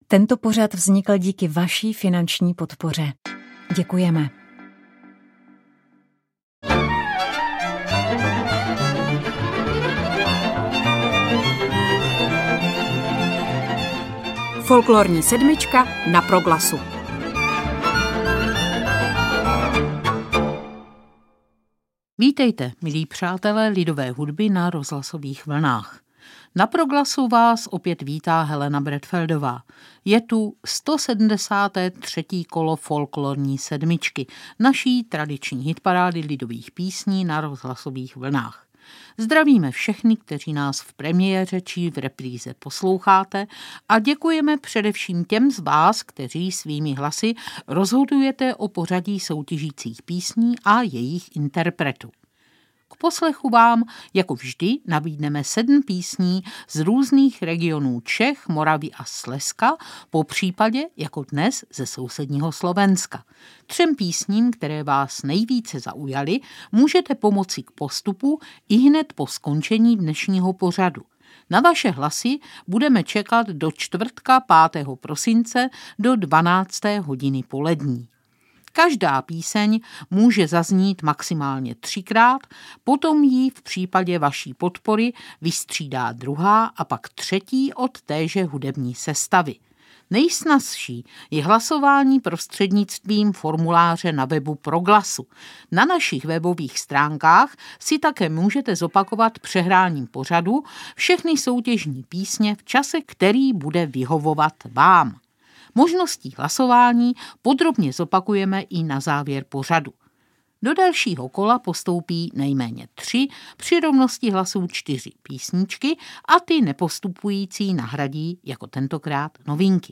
Sobotní Folklorní sedmička na vlnách Proglasu nabízí jako obvykle sedm lidových písní z Moravy i z Čech.
dudy
cimbál